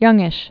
(yŭngĭsh)